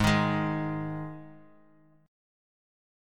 Listen to G#5 strummed